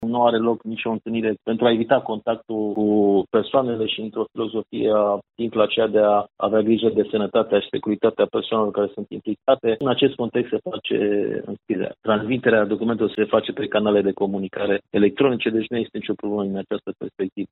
Procesul propriu-zis se realizează online, prin  e-mail sau alte metode de transmitere a actelor, explică șeful Inspectoratului Județean Școlar Timiș, Marin Popescu.